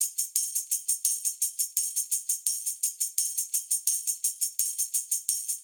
Index of /musicradar/sampled-funk-soul-samples/85bpm/Beats
SSF_TambProc2_85-02.wav